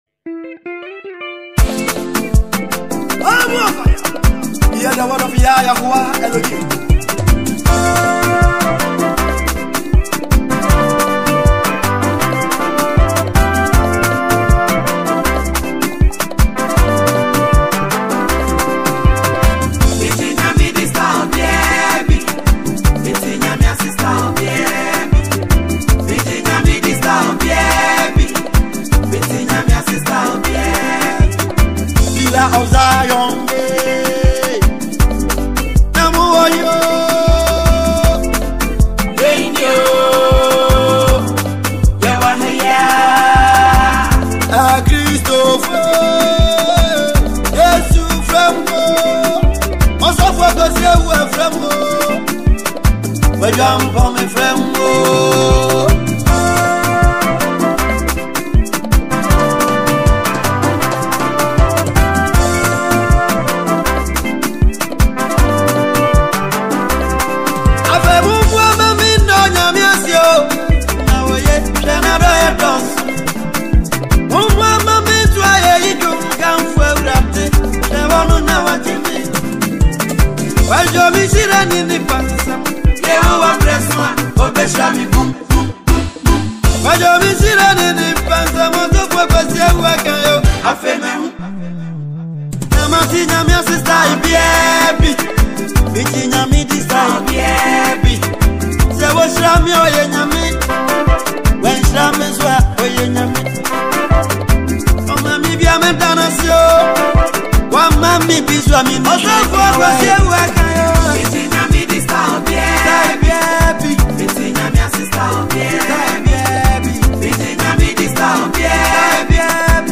Ghana Music Gospel
Ghanaian gospel musician